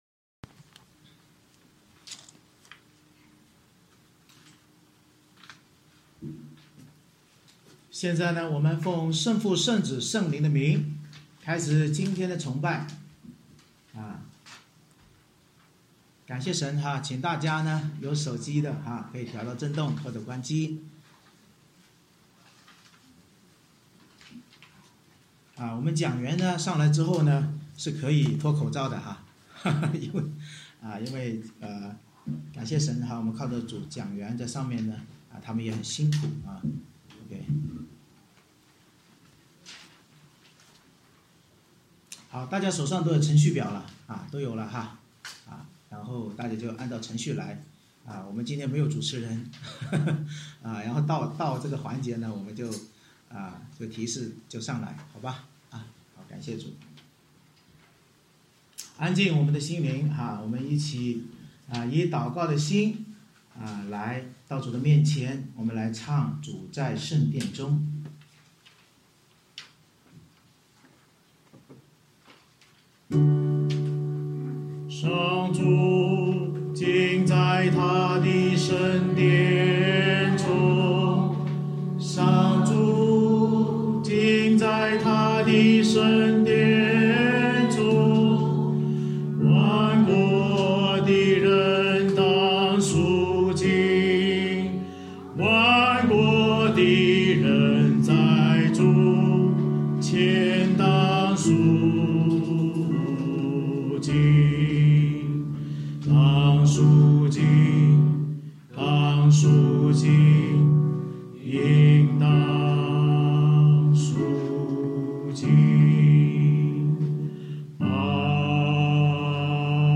诗歌敬拜与讲道《牧师是谁？》及按牧典礼